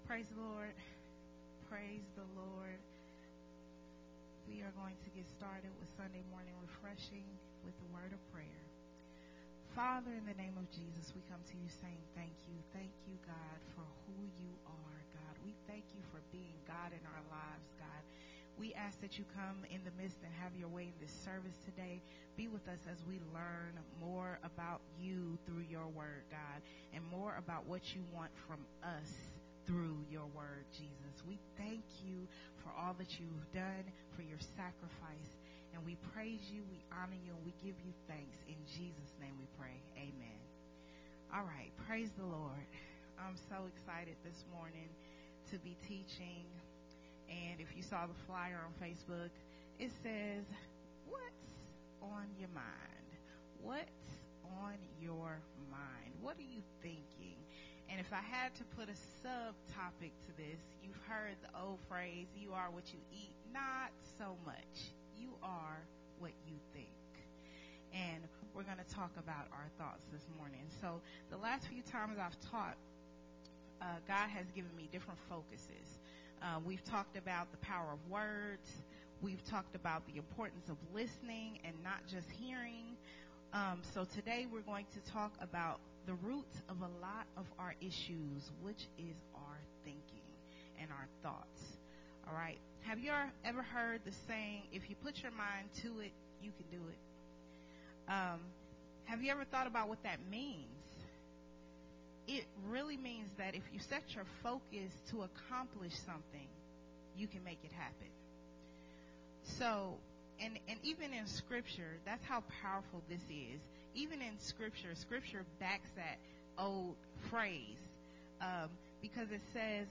a Sunday Morning Refreshing Teaching
recorded at Unity Worship Center on November 21